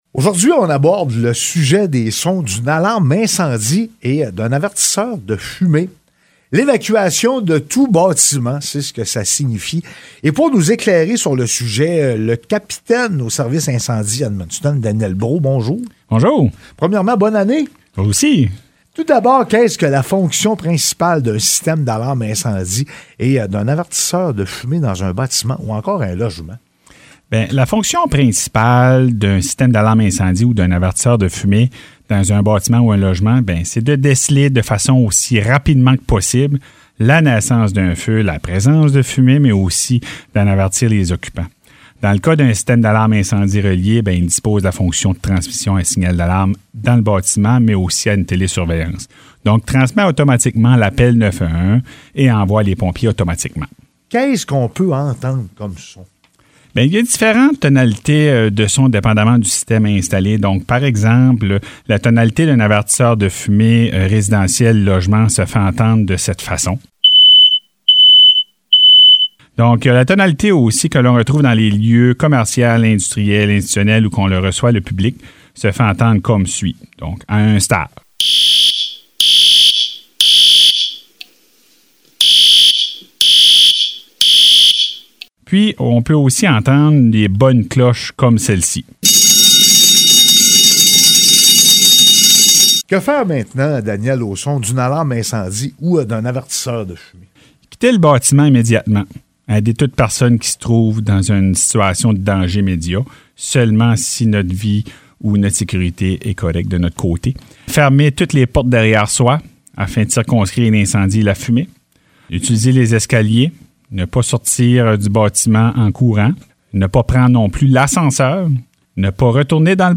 chronique mensuelle diffusée à Frontière FM.
• Signal à deux stages